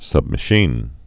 sub·ma·chine gun
(sŭbmə-shēn)